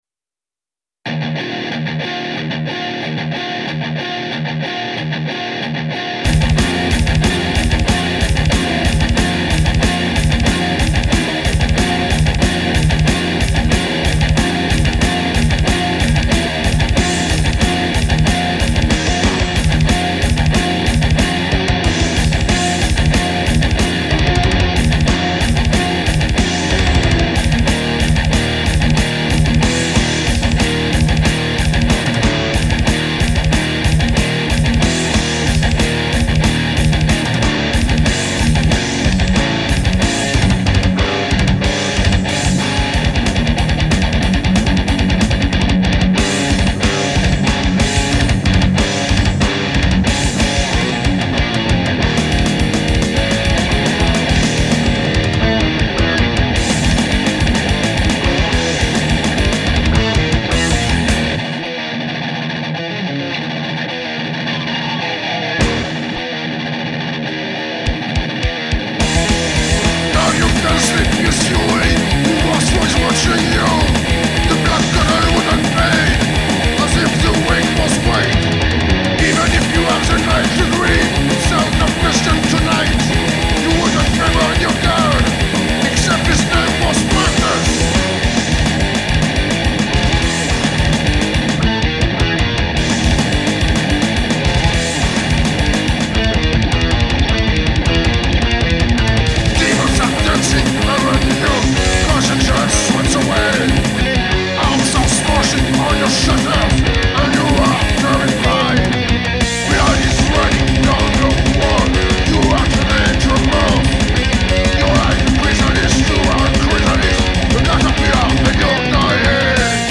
---  THRASH-METAL GAULOIS - UN PROJET NÉ À SAMAROBRIVA ---